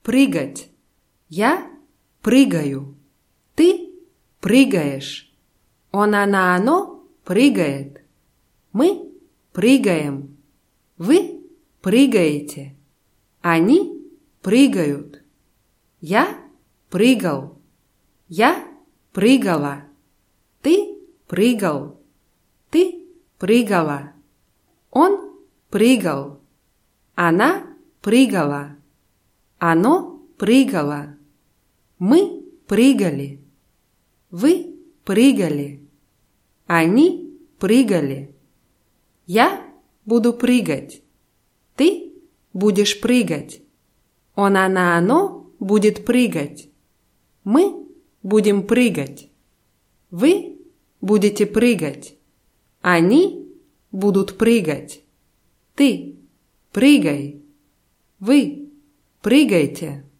прыгать [prýgatʲ]